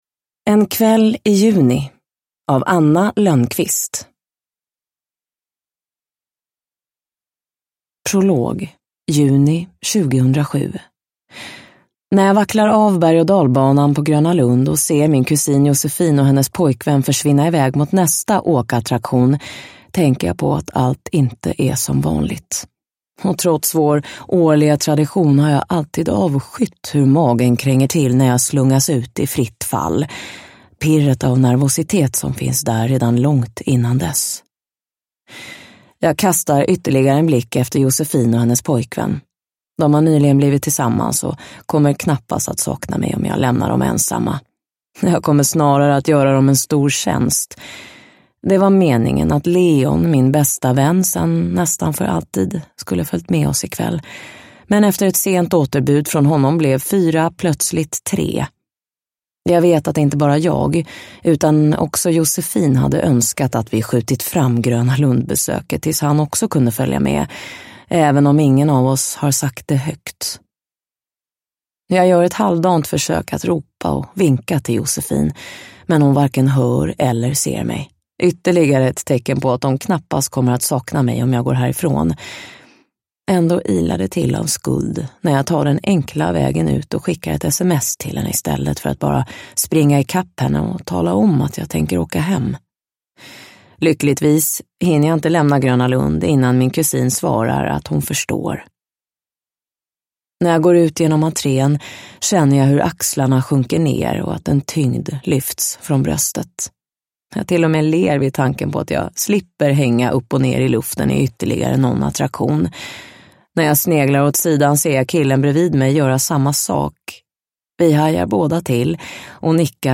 En kväll i juni – Ljudbok – Laddas ner
Uppläsare